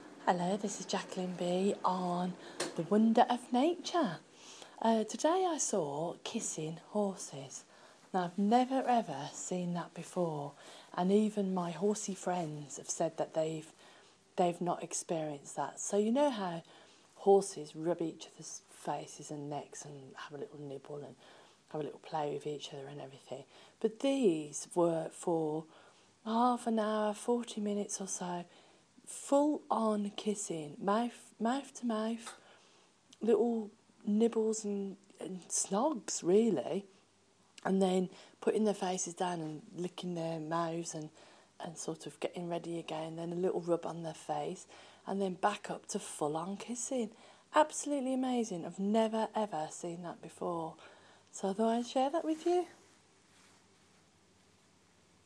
Kissing horses